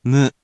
In romaji, 「む」 is transliterated as 「mu」which sounds sort of like the mo in 「move」or the sound a cow makes in English, 「moo」